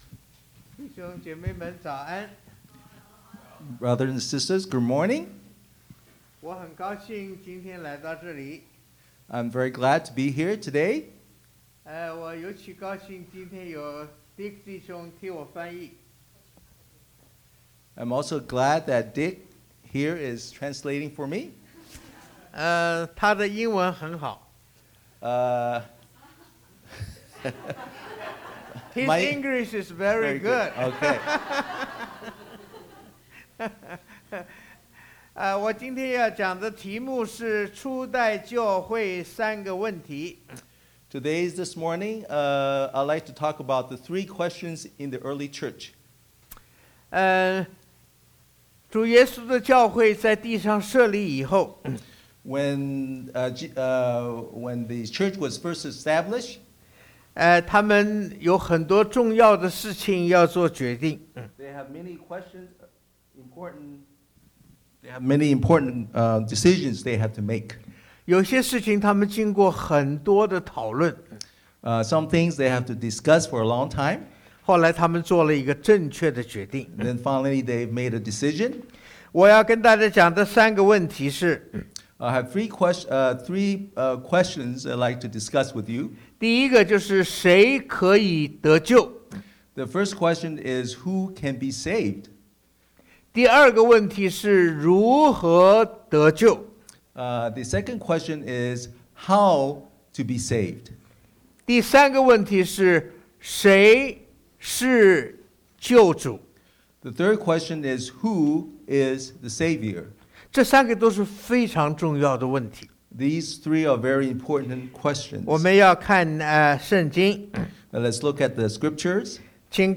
Passage: Galatians 3:26-28 Service Type: Sunday AM